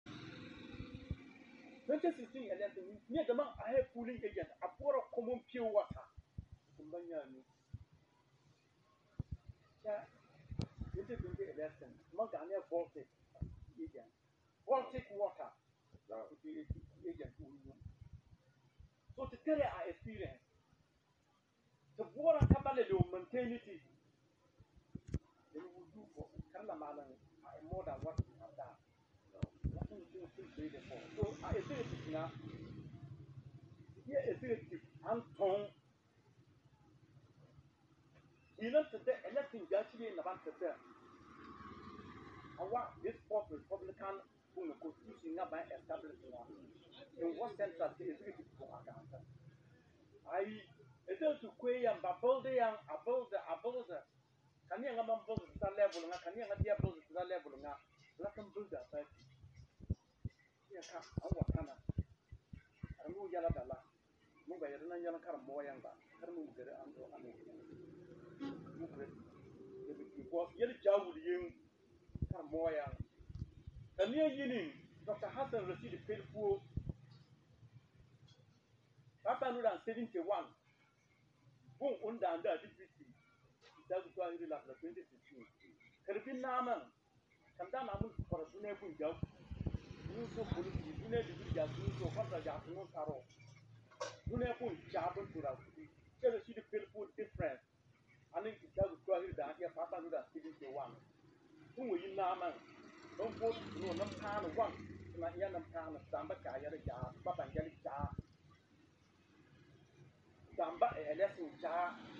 on Radio Mak